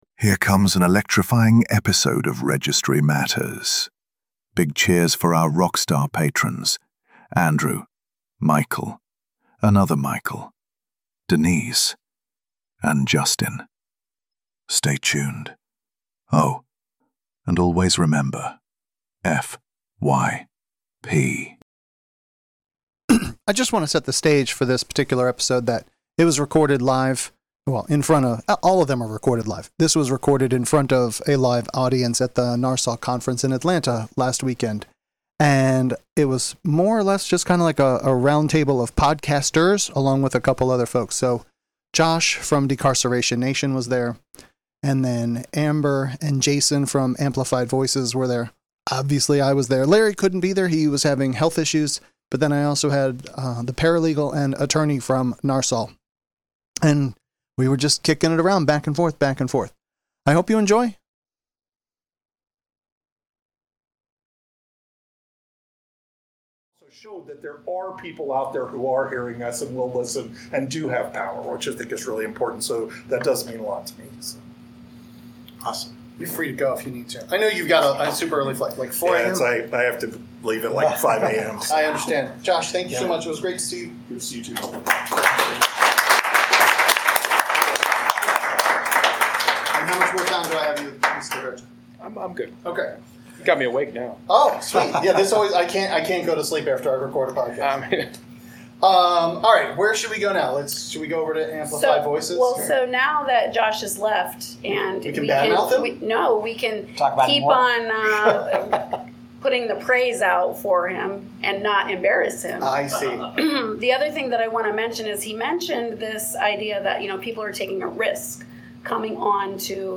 Join the latest *Registry Matters* episode, recorded live at the NARSOL conference, featuring inspiring advocacy stories, passionate discussions by podcasters, legal insights, and a focus on uplifting voices impacted by the system.